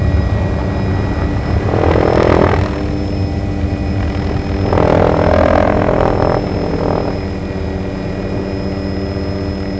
29            <!-- Rotor_Sound -->